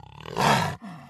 Звуки ягуара